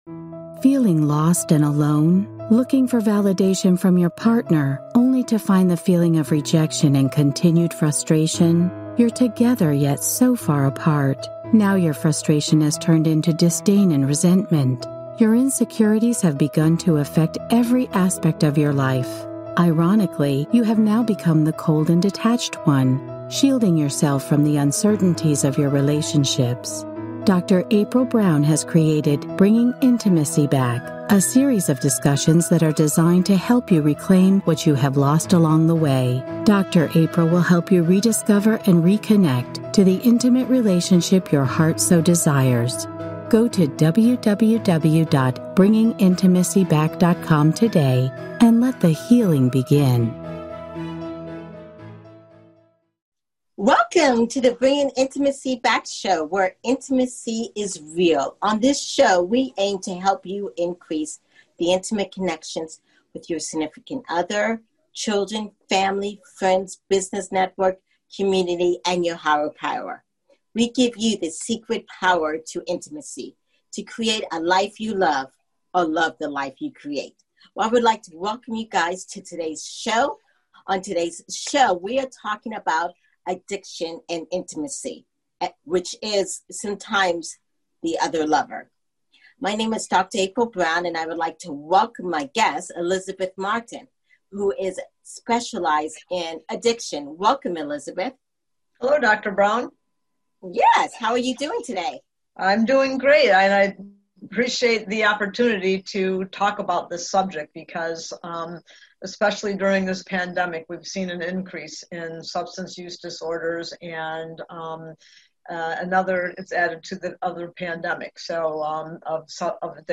Registered Mental Health Counseling Intern and Addictions Counselor